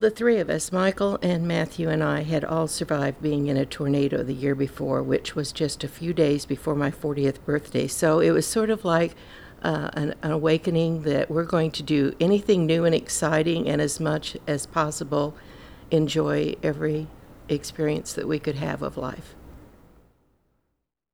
The interview was conducted at the Watkins Museum of History on June 28, 2012.
Oral History